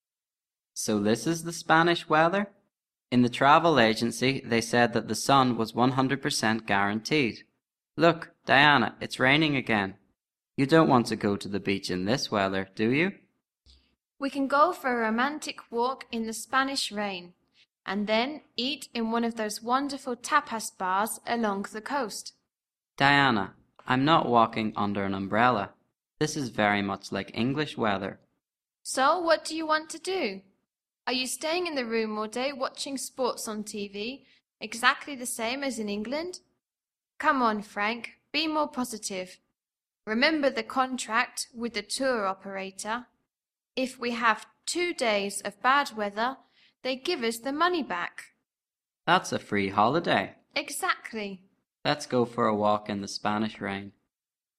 Listen to a couple talking about their holidays in Spain.